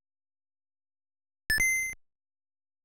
Choosing a week to Auto Skip on the NFL Schedule menu in the retail version
causes a sound to play.
Tecmo Super Bowl (Retail) Auto Skip.wav